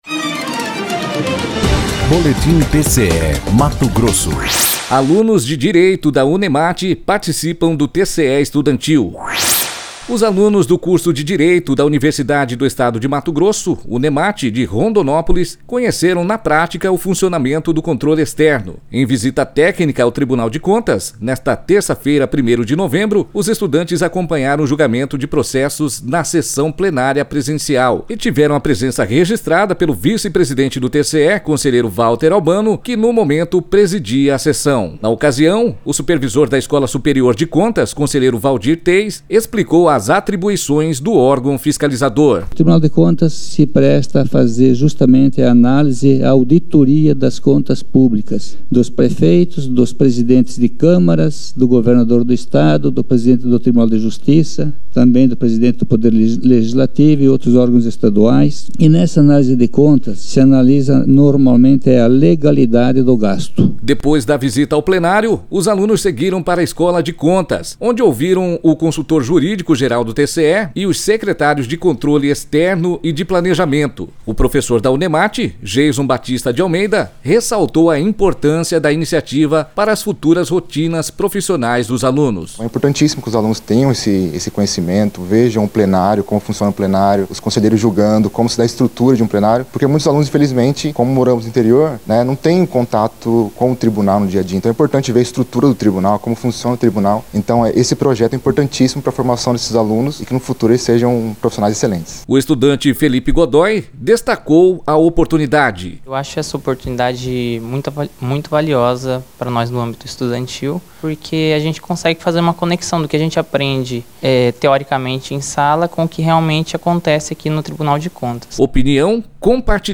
Sonora: Waldir Teis – conselheiro supervisor da Escola Superior de Contas